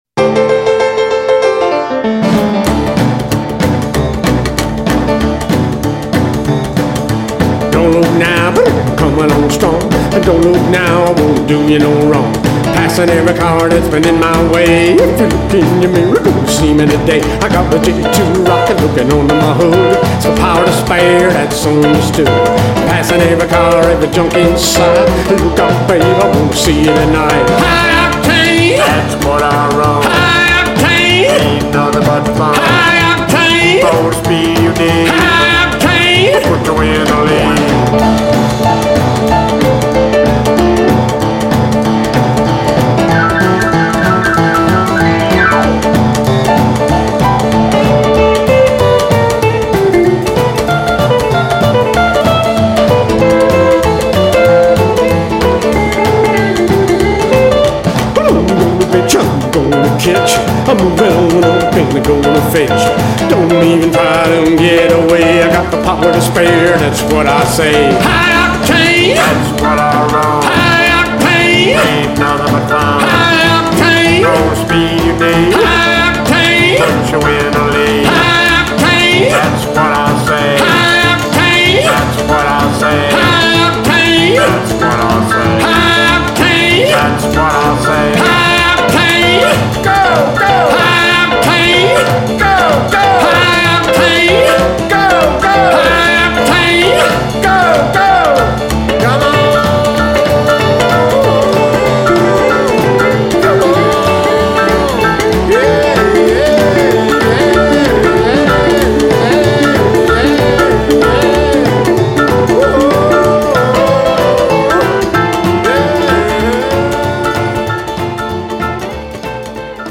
1950's style original rock & roll, and rhythm & blues.